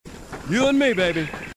Halo Dialogue Snippets